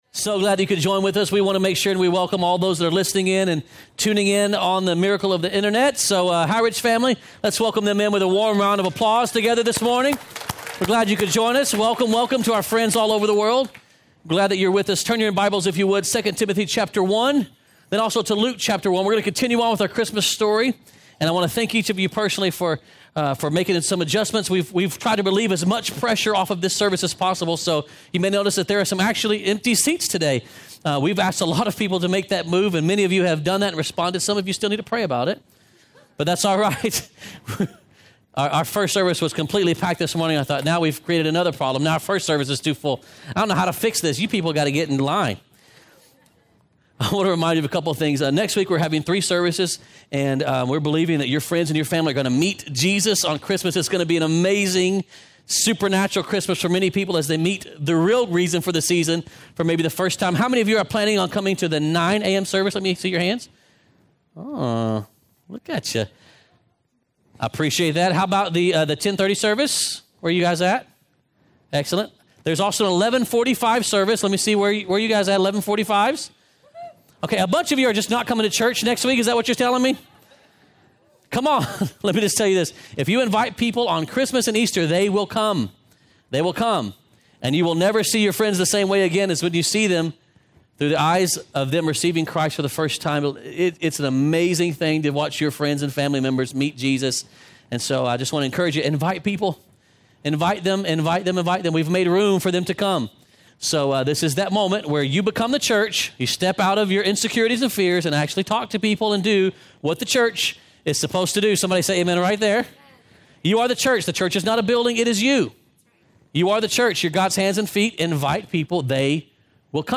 2018 Sermon